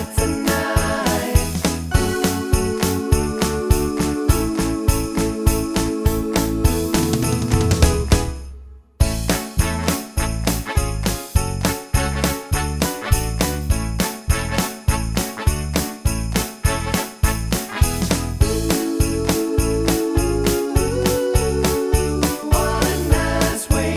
Two Semitones Down Pop (1980s) 3:37 Buy £1.50